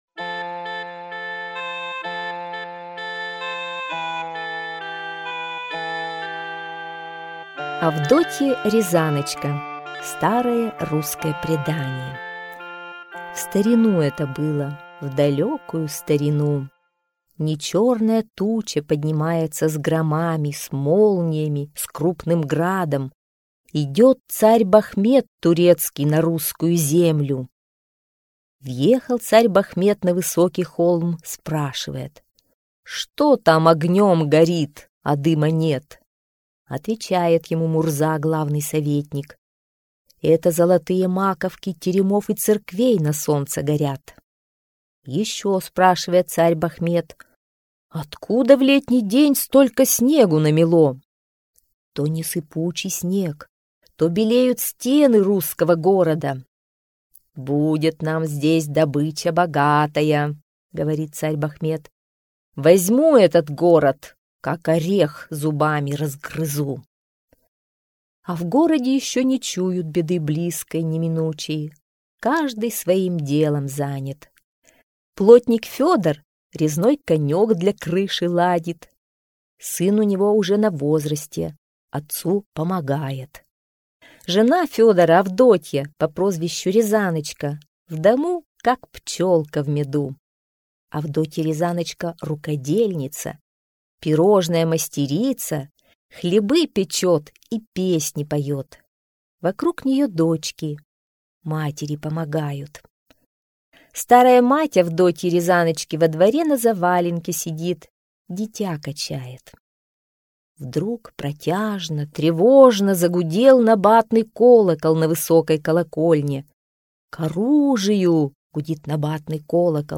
Аудиосказка «Авдотья Рязаночка»